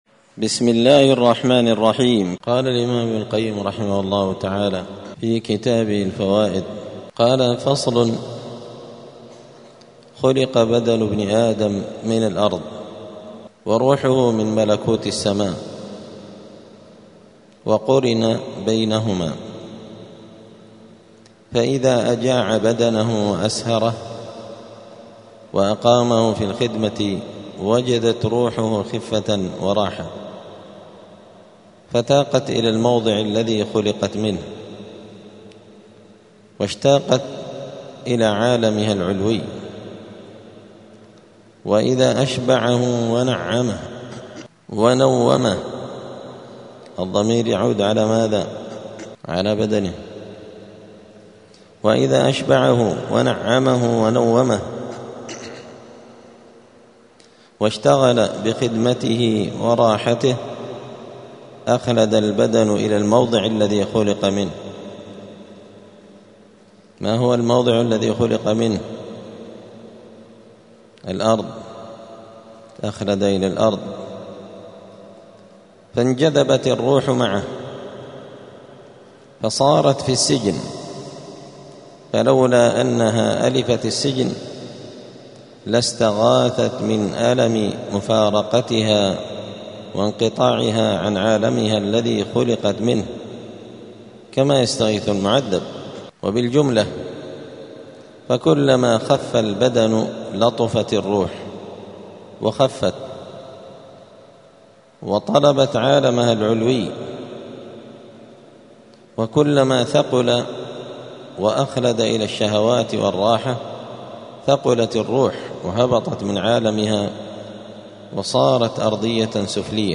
دار الحديث السلفية بمسجد الفرقان قشن المهرة اليمن 📌الدروس الأسبوعية